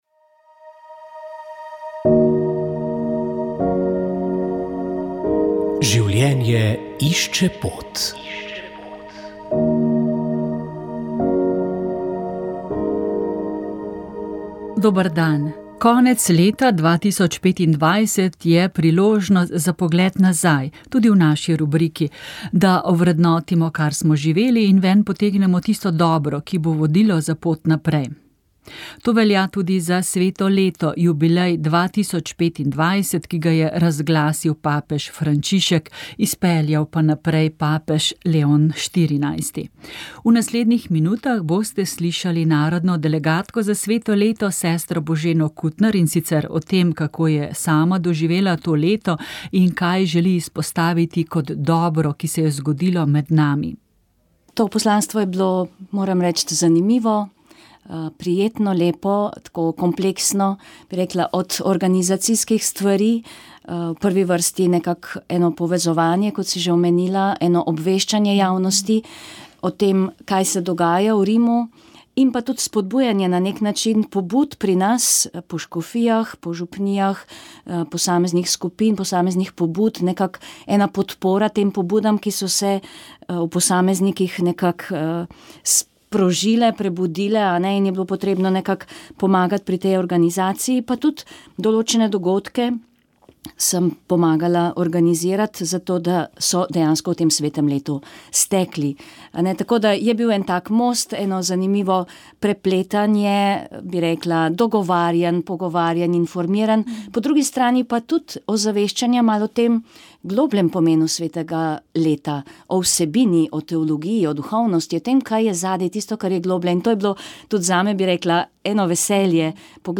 Rožni venec